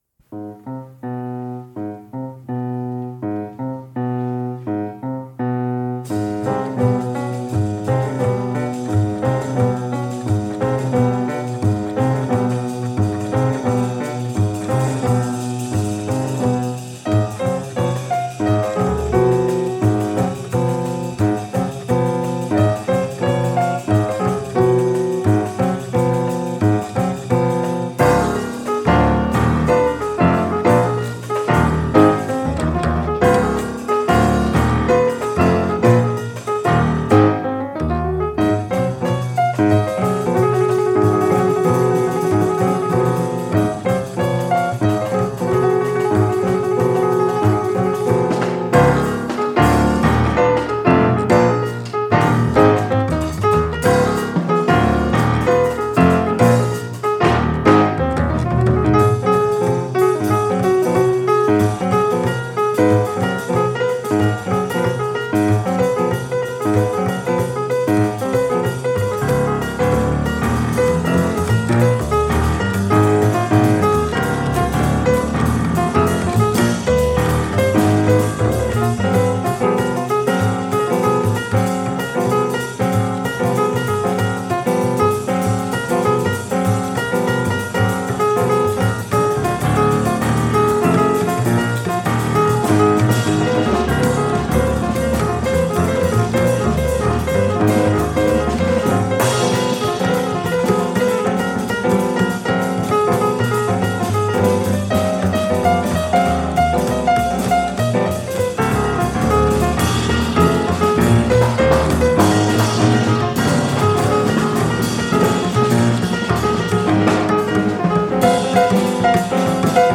Genre: Jazz
Style: Hard Bop, Contemporary Jazz, Free Jazz